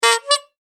パフッ3.mp3